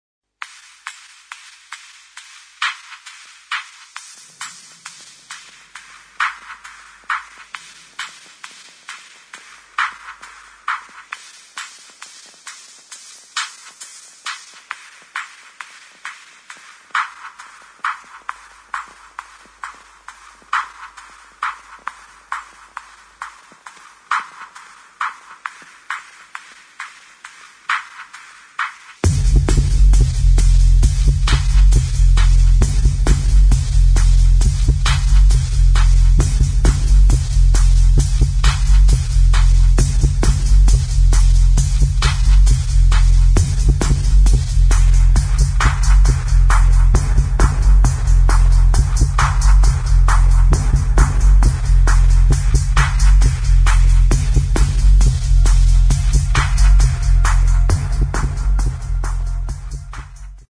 [ BASS | TECHNO]